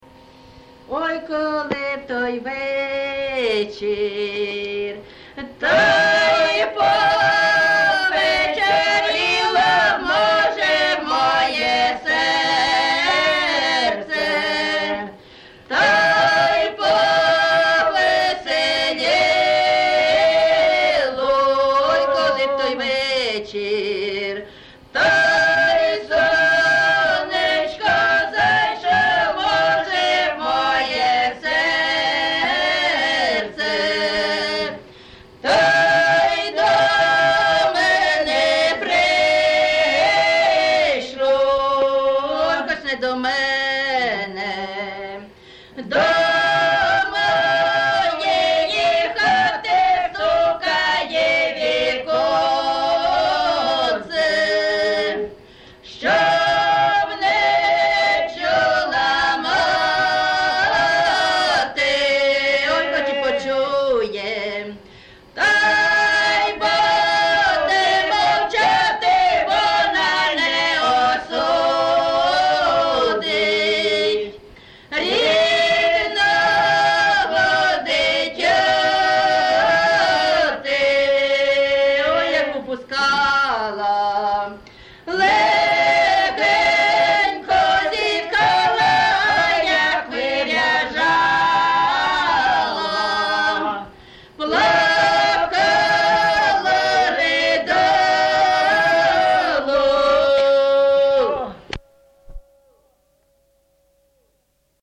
ЖанрПісні з особистого та родинного життя
Місце записус. Яблунівка, Костянтинівський (Краматорський) район, Донецька обл., Україна, Слобожанщина